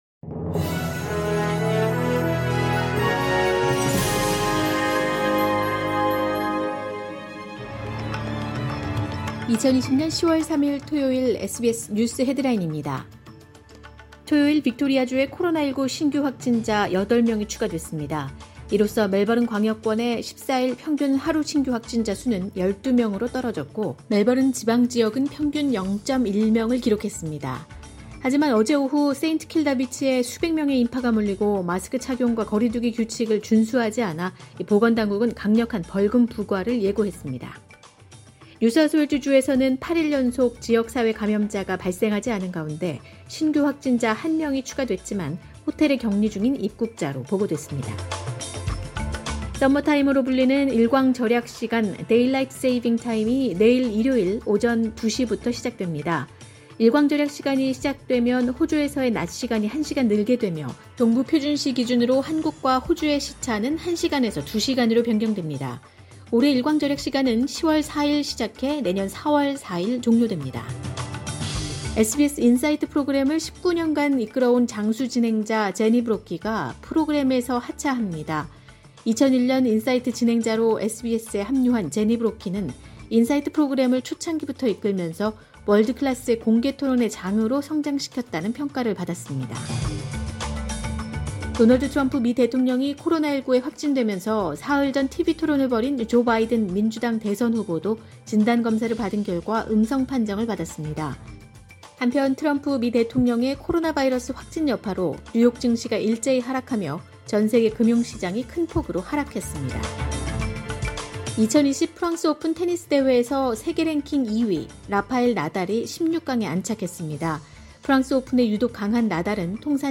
2020년 10월 3일 토요일 오전의 SBS 뉴스 헤드라인입니다.